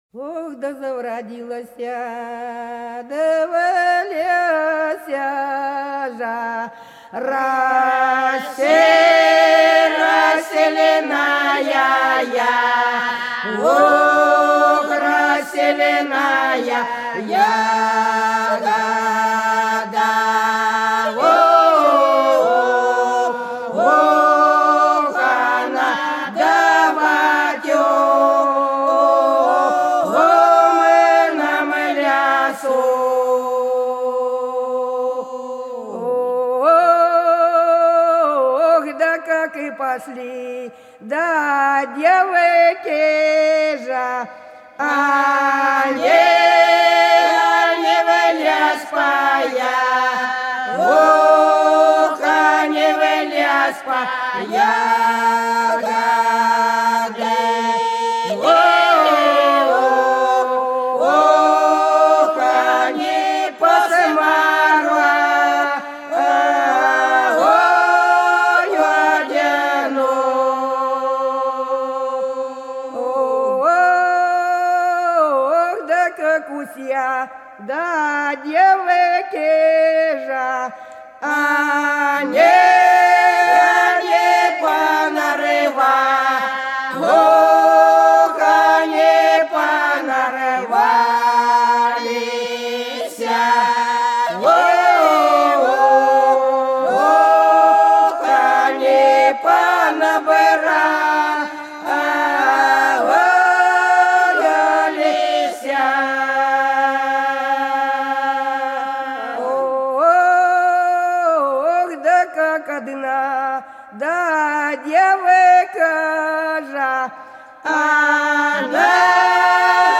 Хороша наша деревня Да завродилася в лесе рассильная ягода - протяжная (с. Иловка)